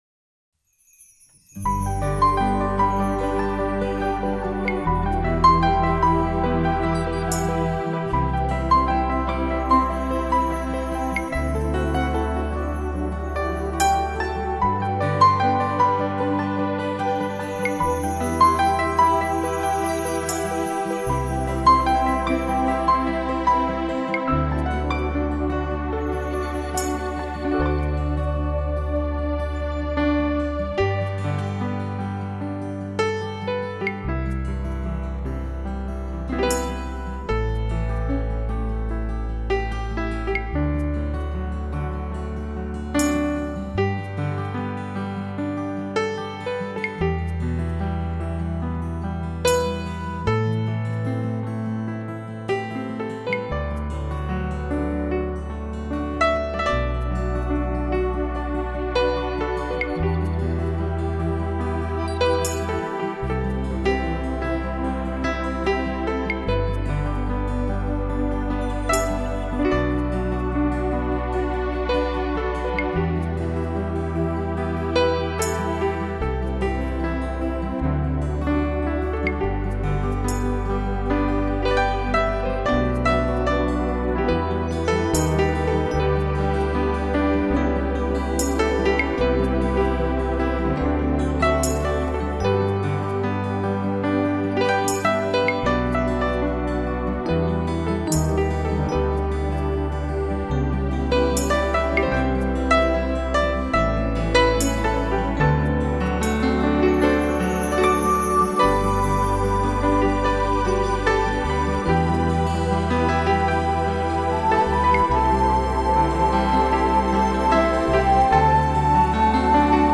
一种全新的曲风，圆舞曲式的旋律，可从音乐裡“看”到花的忘情飞舞，“听”到花的窃窃私语，甚至”闻”到花的淡淡幽香。